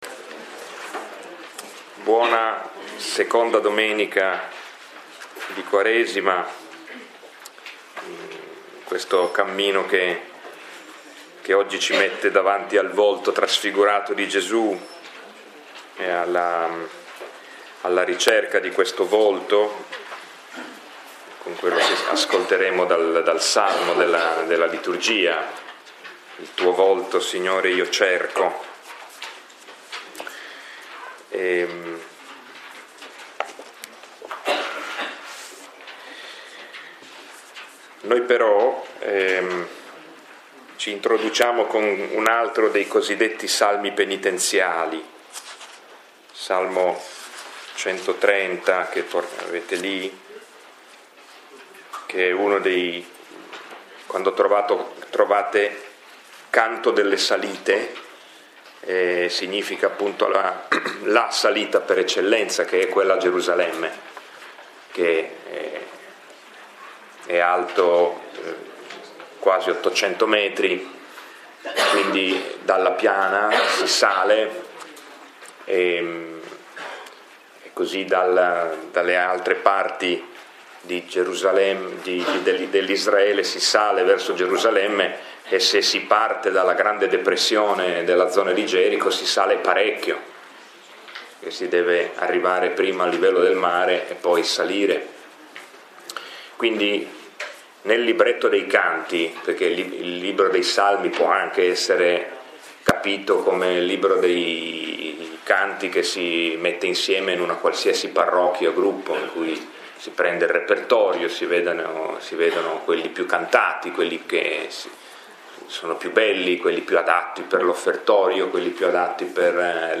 Lectio 5 – 21 febbraio 2016